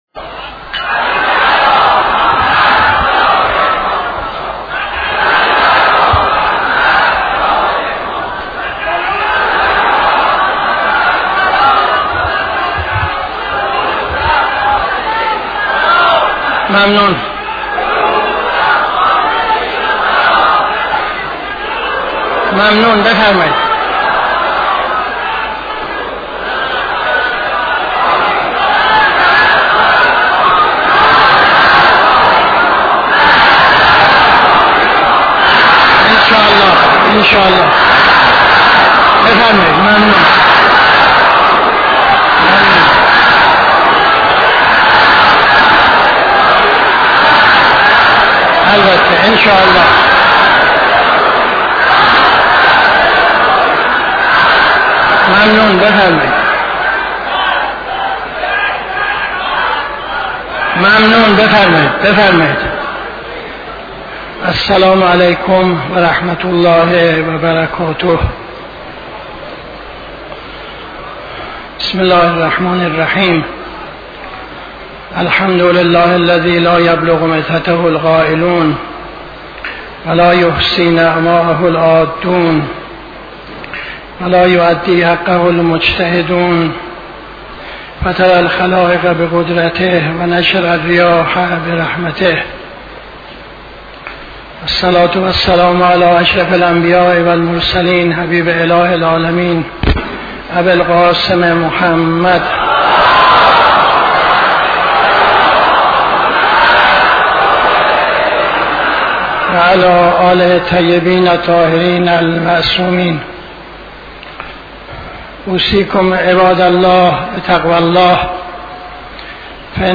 خطبه اول نماز جمعه 08-04-75